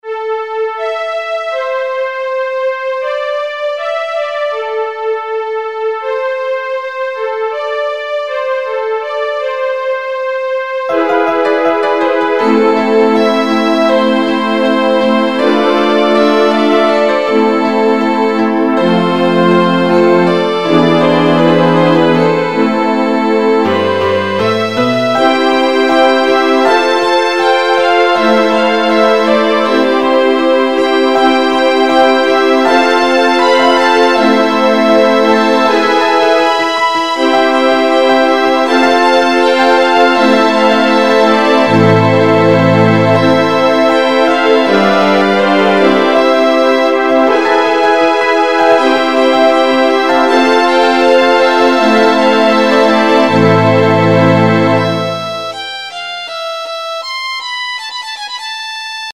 ממש ממש מרגיע ויפה...
ממש אהבתי את הההשתלבות של הכלים כל אחד בתורו...
מנגינה מנחמת,סיום מתוק.
...האיכות ירדה ולא שומעים טוב את הפסנתר ולא מזהים את הכינור.